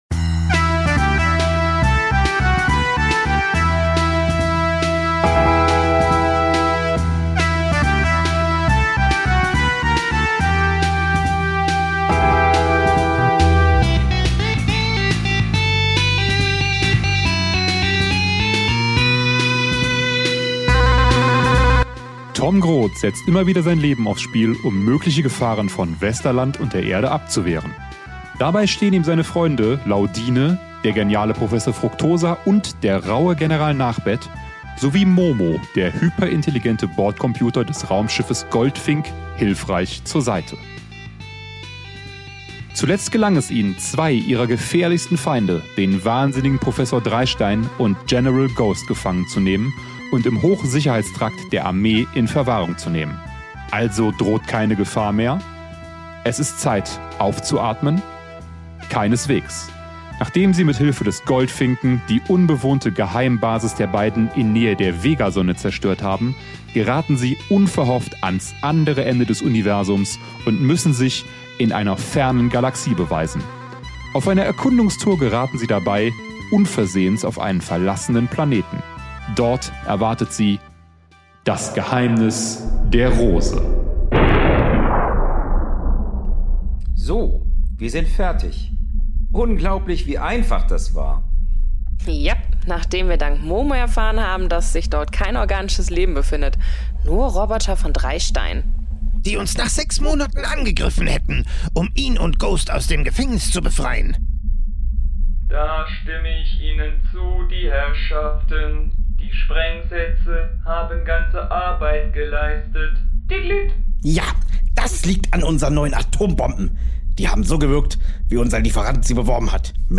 Sozusagen eine vertonte Spiegelwelt.